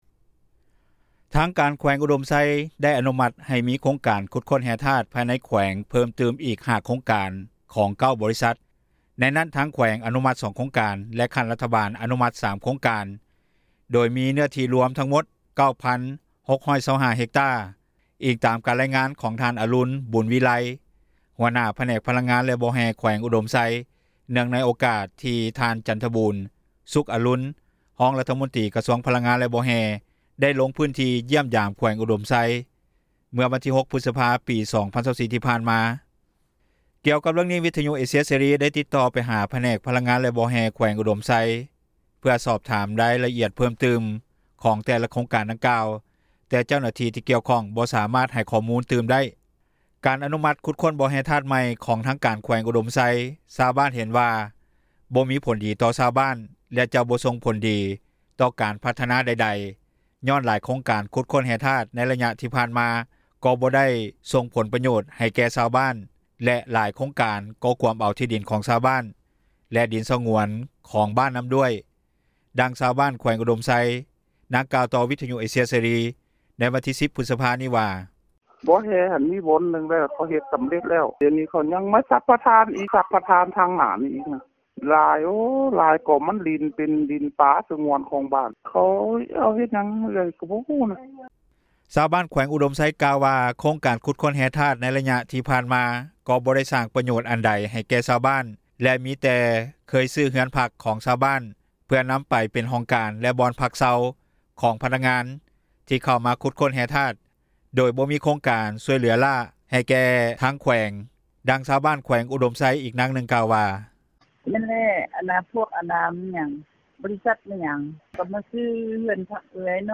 ດັ່ງຊາວບ້ານ ແຂວງອຸດົມໄຊນາງກ່າວຕໍ່ ວິທຍຸເອເຊັຽເສຣີ ໃນວັນທີ 10 ພຶດສະພານີ້ວ່າ:
ດັ່ງຊາວບ້ານ ຢູ່ແຂວງອຸດົມໄຊ ອີກນາງໜຶ່ງກ່າວວ່າ:
ດັ່ງອະດີດເຈົ້າໜ້າທີ່ລັດ ທ່ານນີ້ກ່າວວ່າ: